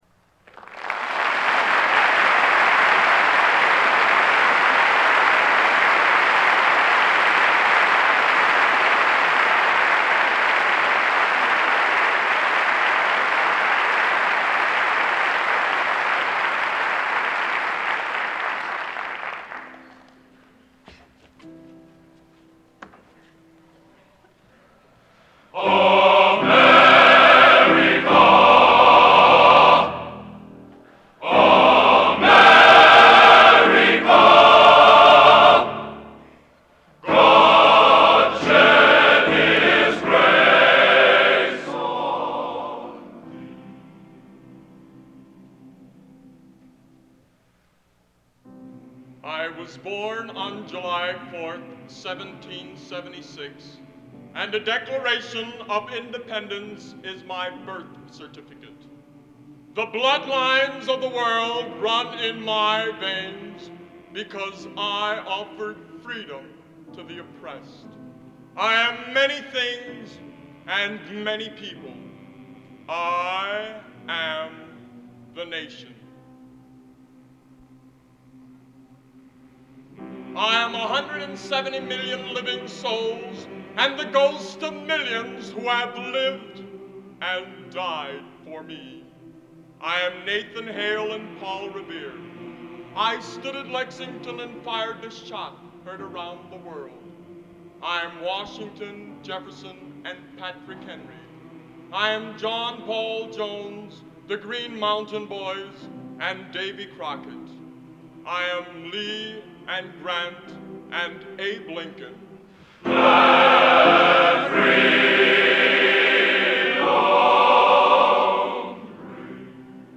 Genre: Original Composition Patriotic | Type: Solo